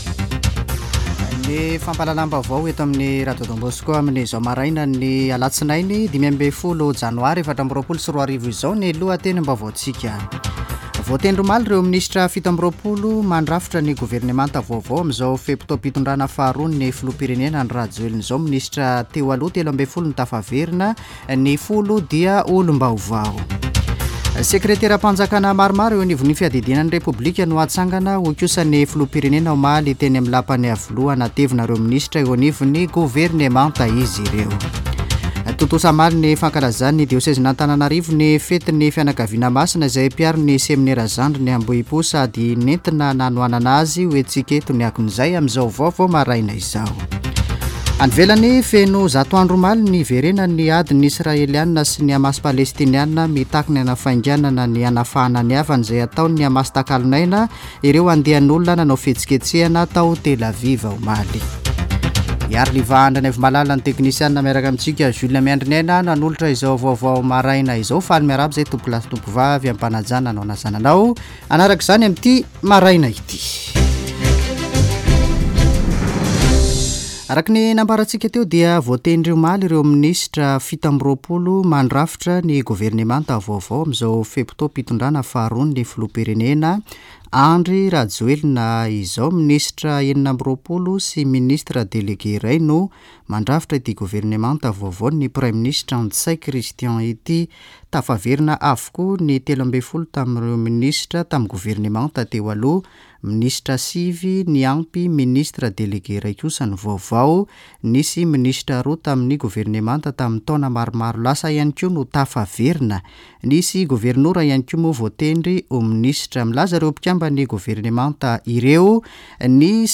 [Vaovao maraina] Alatsinainy 15 janoary 2024